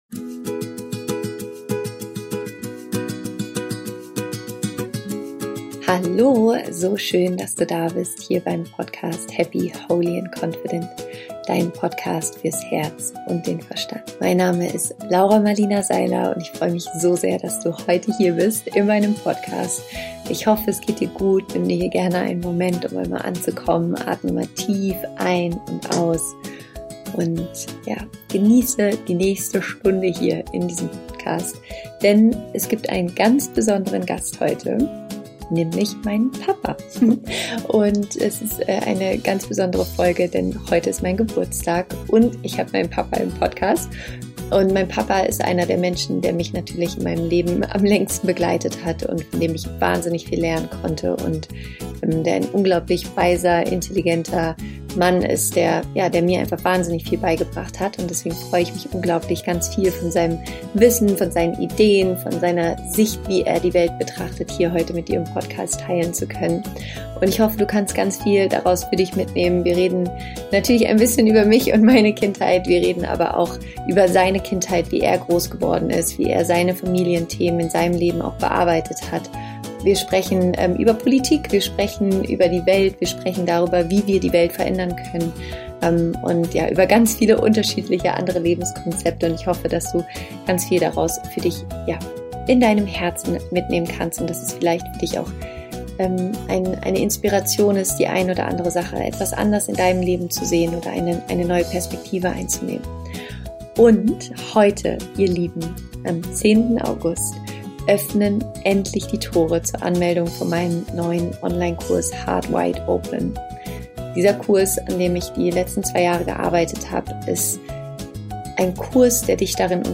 Panta rhei: Warum alles im Leben fließt - Mein Papa zu Gast im Interview